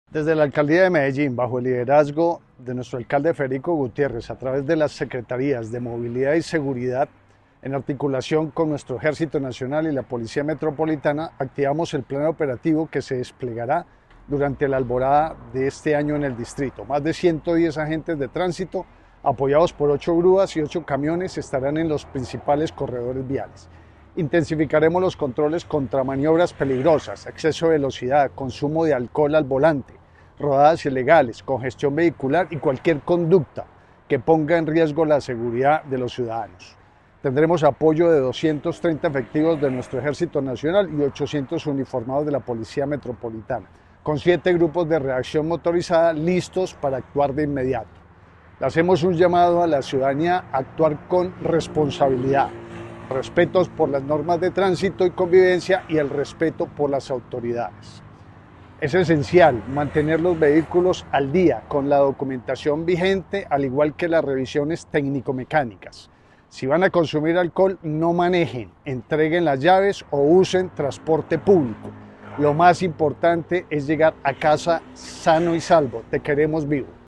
Declaraciones del secretario de Movilidad, general (r) Pablo Ruiz
Declaraciones-del-secretario-de-Movilidad-general-r-Pablo-Ruiz.mp3